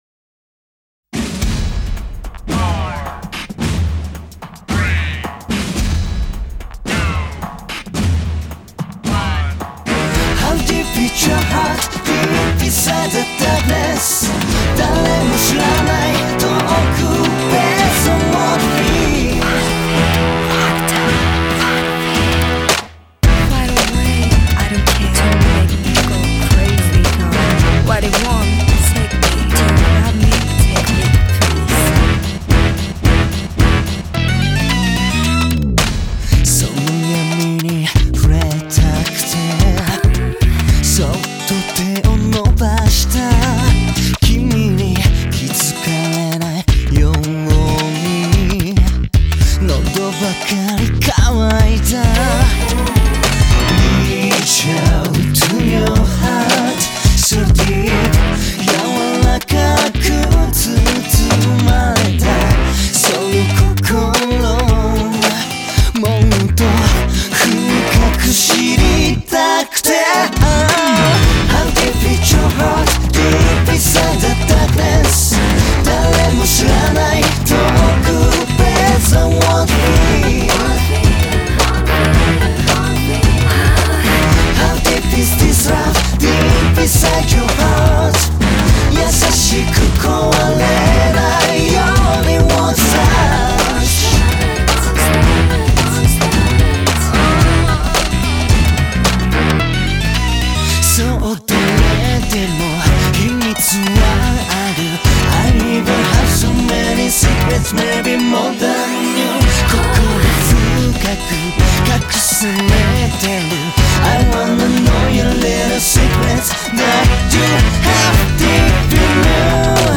Это уже современная обработка! Но веселая.